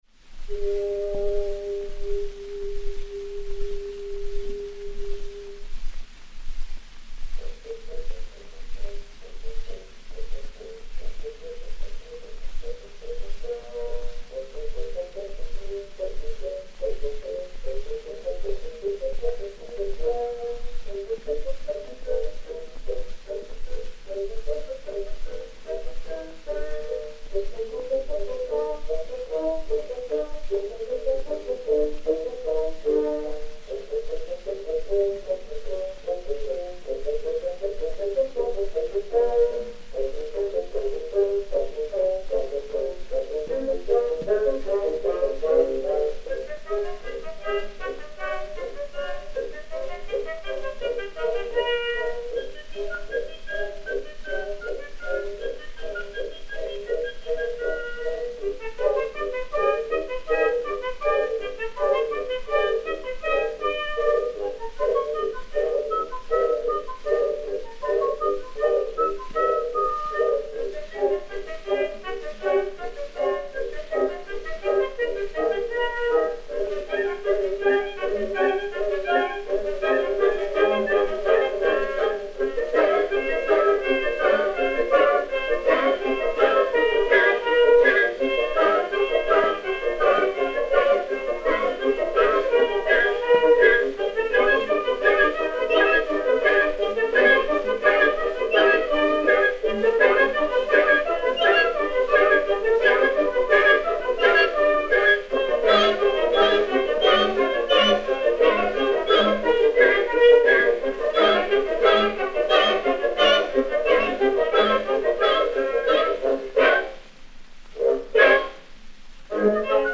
Orchestre dir.
Pathé saphir 90 tours n° 7147-2, réédité sur 80 tours n° 6402, enr. à Paris vers 1912